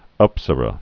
(ŭpsə-rə)